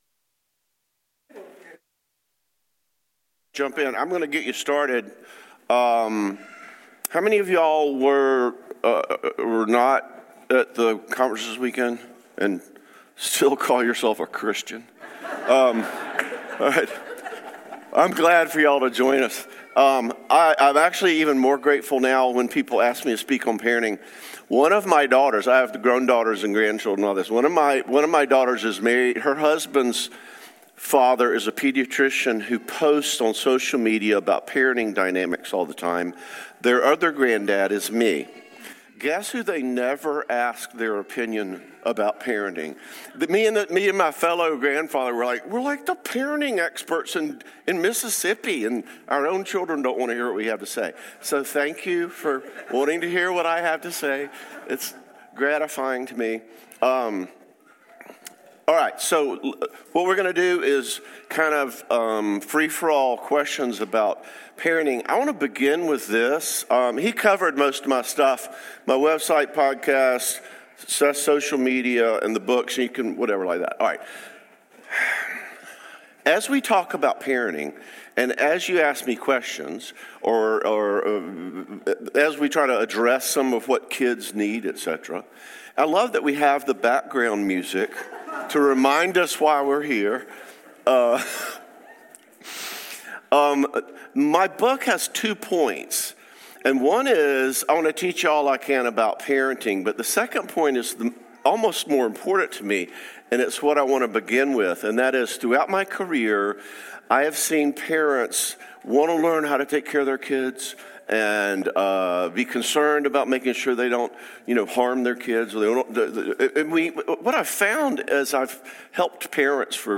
Parenting Q&A
Real Community Service Type: Lectures Topics